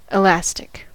elastic: Wikimedia Commons US English Pronunciations
En-us-elastic.WAV